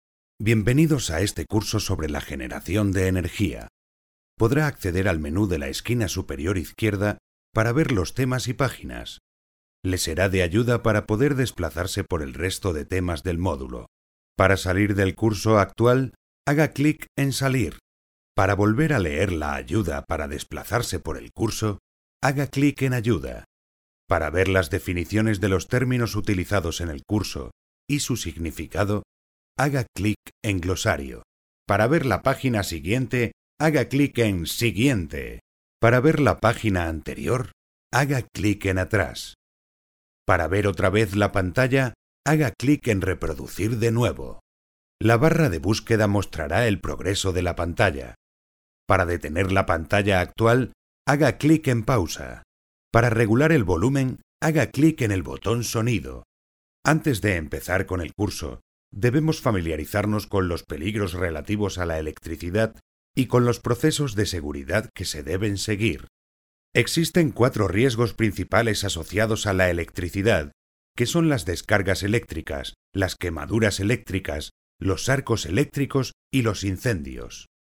Sprechprobe: eLearning (Muttersprache):
Experienced Spanish home studio voiceover talent and producer.